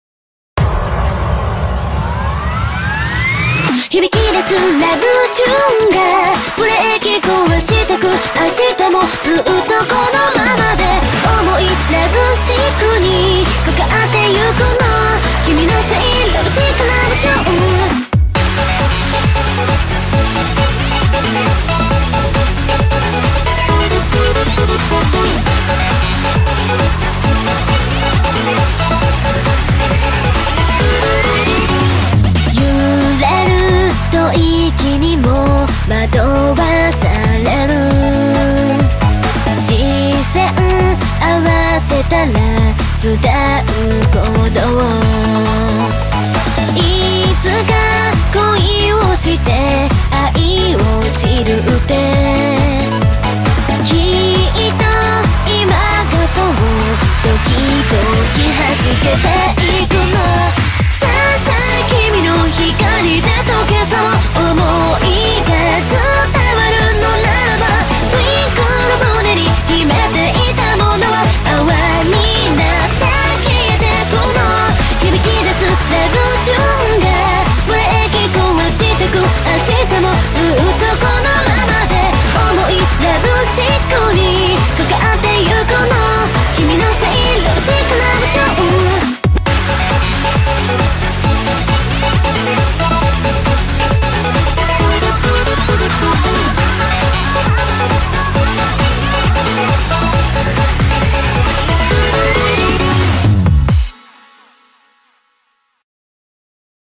BPM135
Audio QualityPerfect (Low Quality)